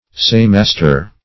Search Result for " saymaster" : The Collaborative International Dictionary of English v.0.48: Saymaster \Say"mas`ter\, n. A master of assay; one who tries or proves.